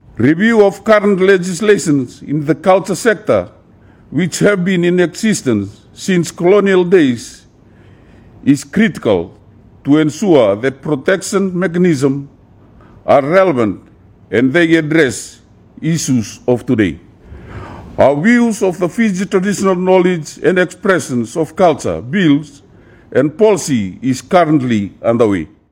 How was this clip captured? He made these comments at the 8th Melanesian Spearhead Group Ministers of Culture and Arts meeting.